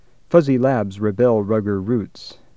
fussy/fuzzy, [s] at 400 msec. in first spectrogram has no low-pitch voicing; [z] at 450 msec. in second spectrogram has low pitch voicing from about 0-500 Hz.
At 850 msec. of the second spectrogram, F1, F2, F3 all drop approaching the closure for [b], indicating bilabial place of articulation.
Second spectrogram, around 2000 msec.:  F1 around 300 Hz. and F2 around 1700 indicate a high vowel; F2 is rather high for an /u/, but I probably said something much fronter than this (like IPA barred u), accounting for the high F2.